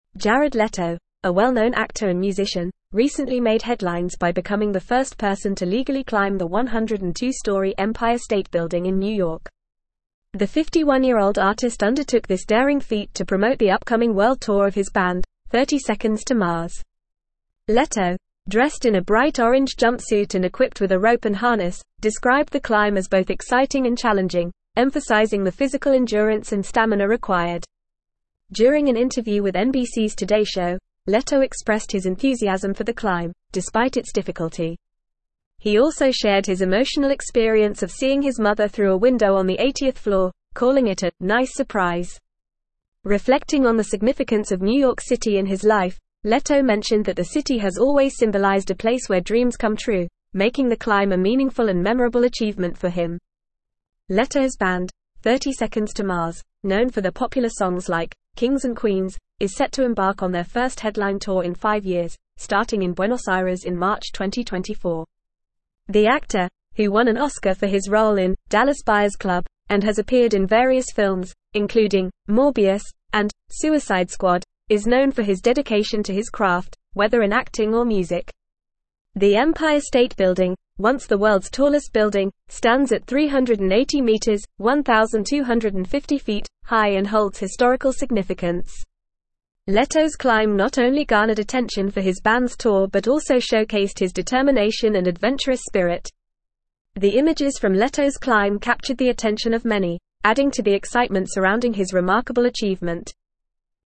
Fast
English-Newsroom-Advanced-FAST-Reading-Jared-Leto-Scales-Empire-State-Building-for-Band.mp3